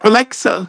synthetic-wakewords
ovos-tts-plugin-deepponies_Discord_en.wav